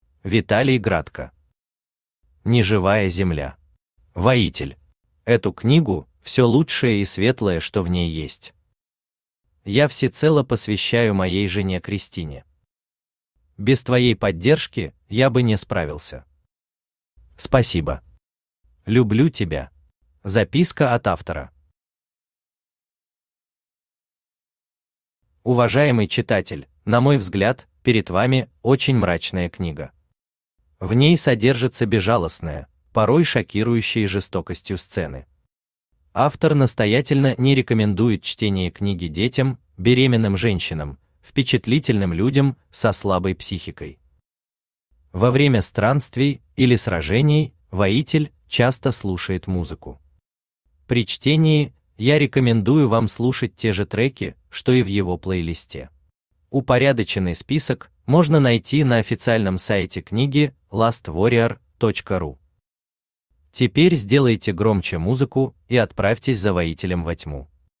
Воитель Автор Виталий Градко Читает аудиокнигу Авточтец ЛитРес.